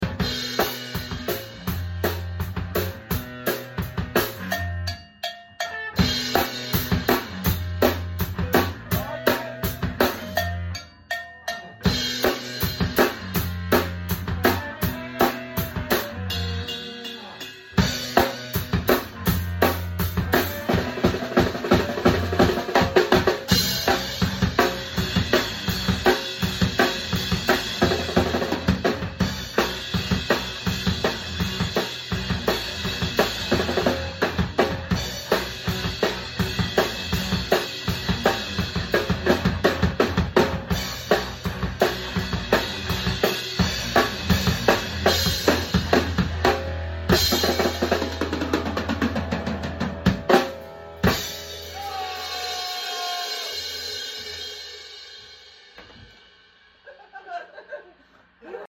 Just jamming Peewee circus metal